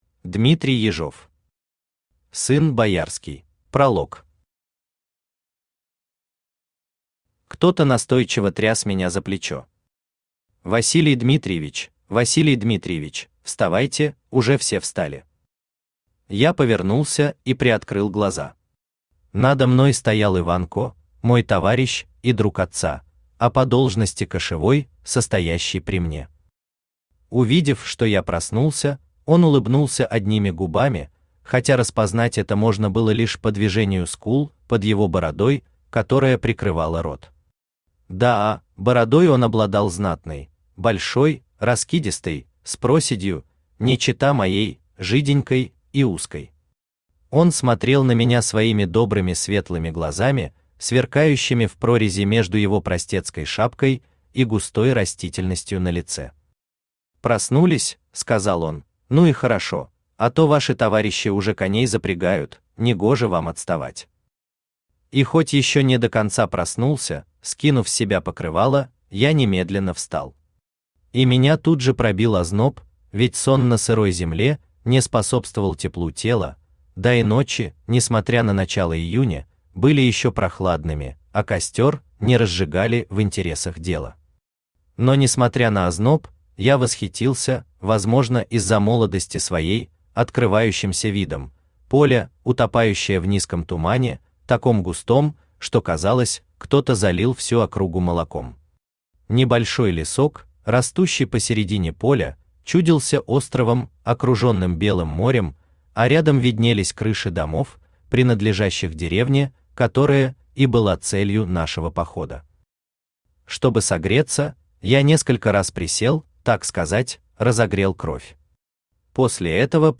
Аудиокнига Сын боярский | Библиотека аудиокниг
Aудиокнига Сын боярский Автор Дмитрий Ежов Читает аудиокнигу Авточтец ЛитРес.